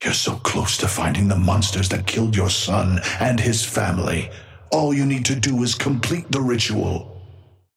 Amber Hand voice line - You're so close to finding the monsters that killed your son and his family.
Patron_male_ally_orion_start_04.mp3